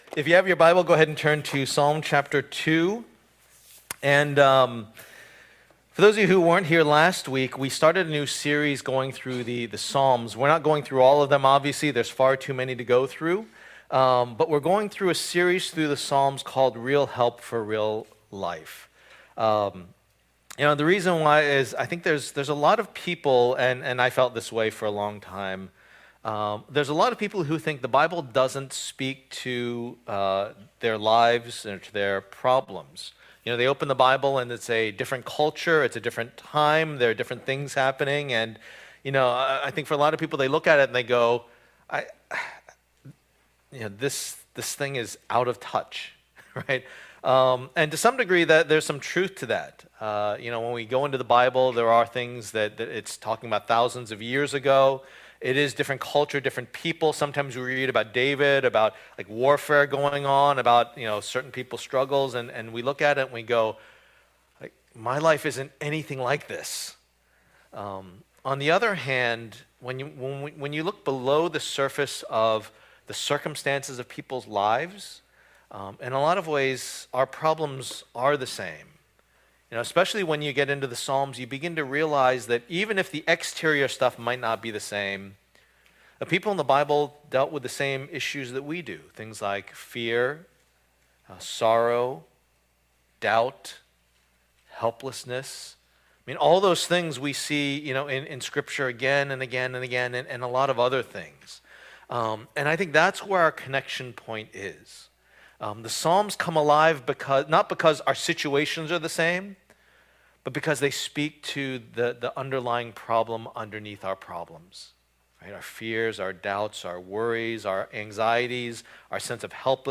Passage: Psalm 2:1-12 Service Type: Lord's Day